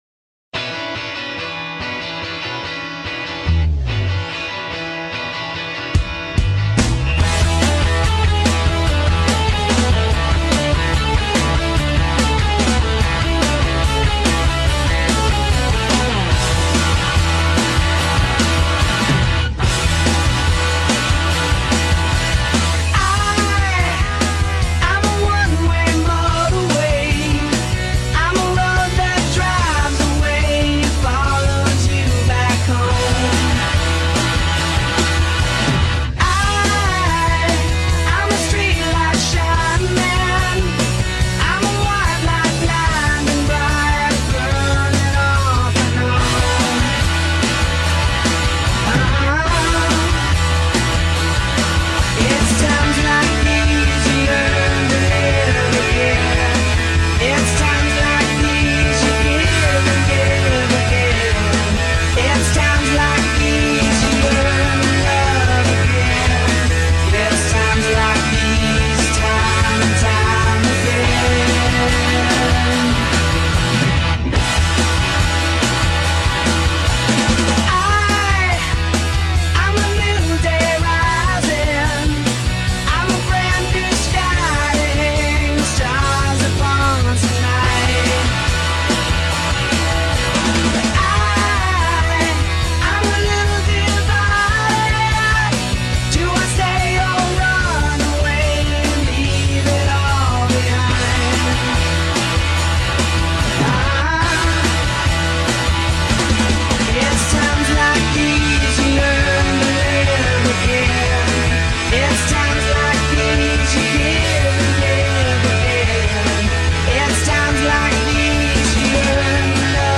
track in key of D (up a whole step)